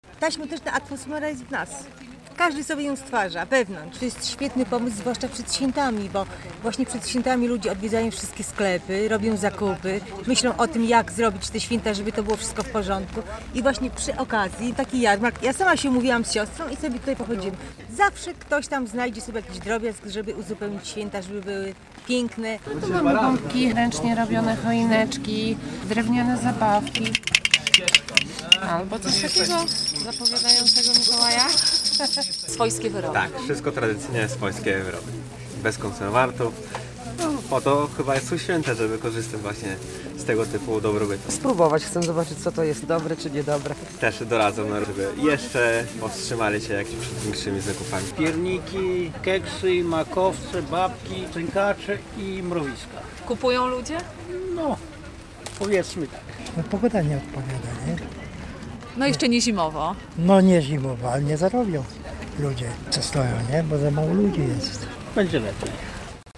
Jarmark przedświąteczny - relacja